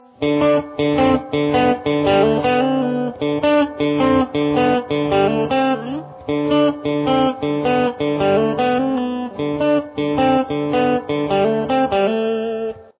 The intro riff to this classic song is pretty easy to play.